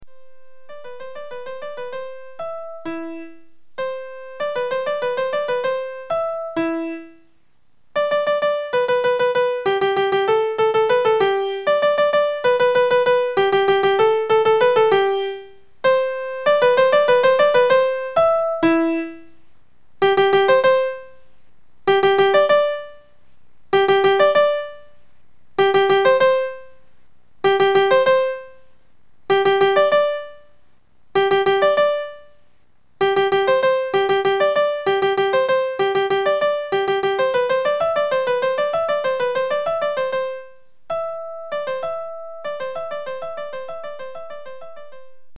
ariafigaro.mp3